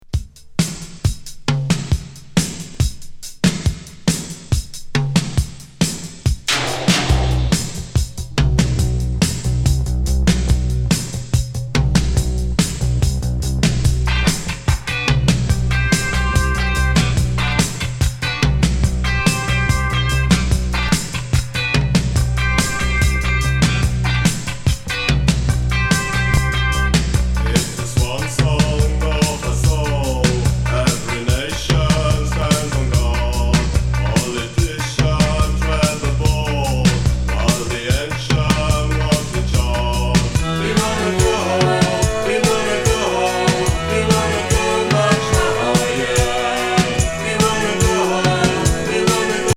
イスラエル人を中心に結成されたベルジャン・NWグループの1ST!イスラム風味な
リズム・ボックスもクールなB4